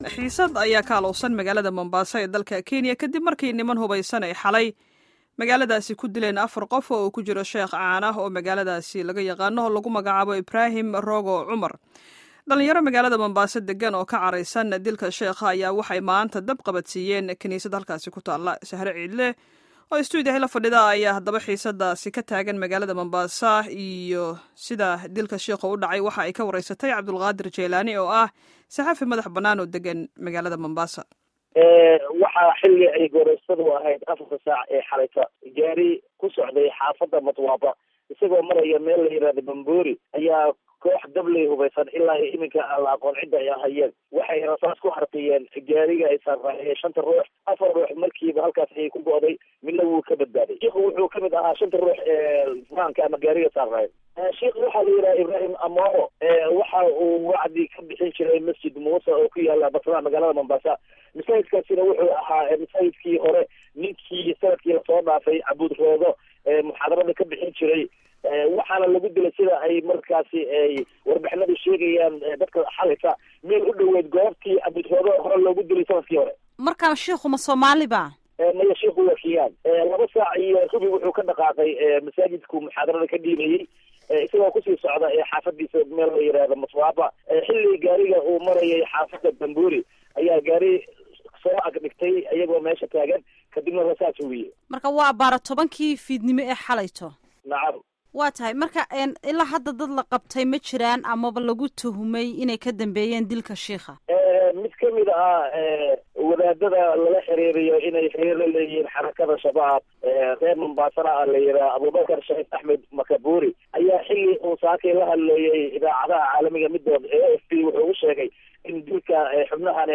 Waraysi xaalaadda Mombasa